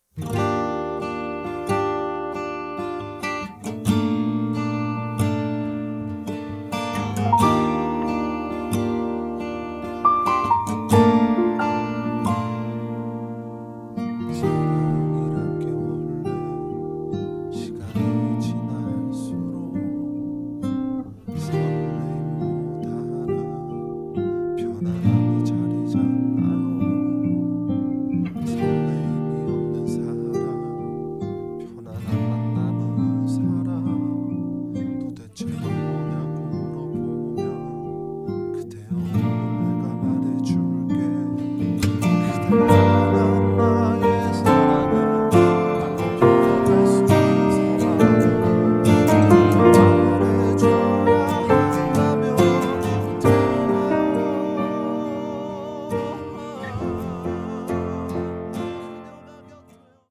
음정 -1키 3:43
장르 가요 구분 Voice Cut